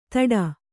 ♪ taḍa